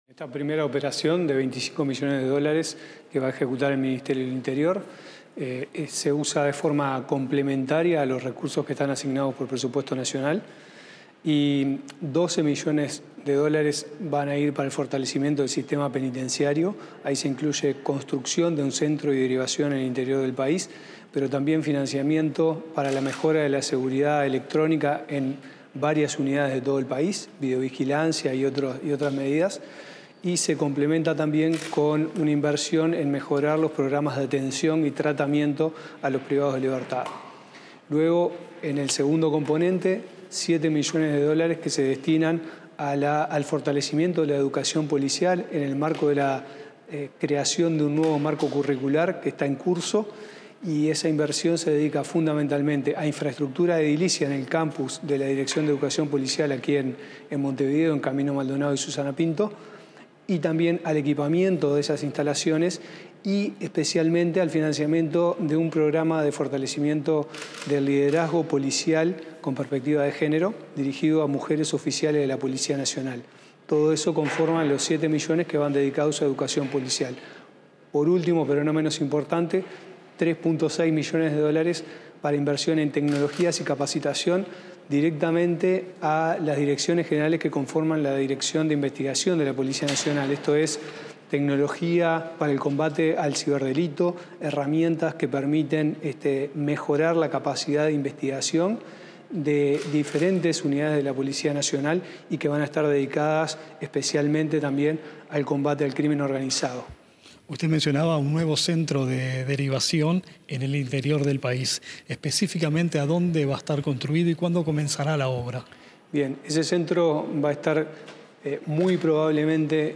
En diálogo con Comunicación Presidencia